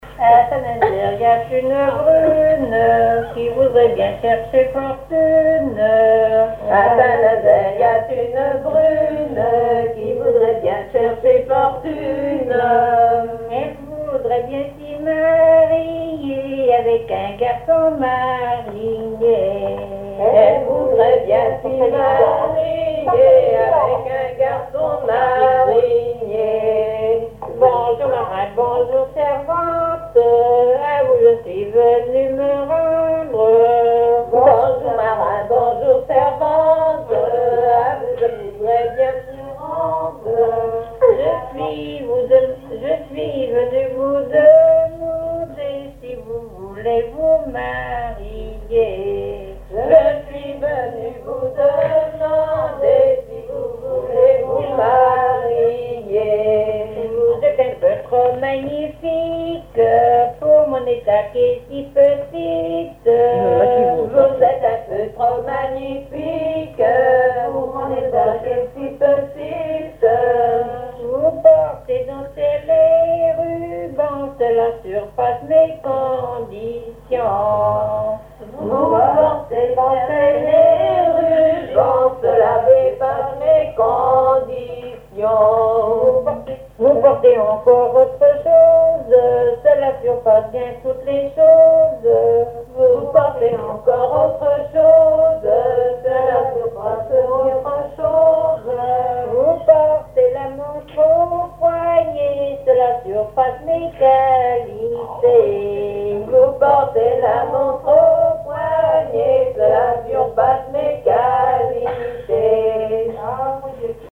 danse : ronde à la mode de l'Epine
Veillée de chansons
Pièce musicale inédite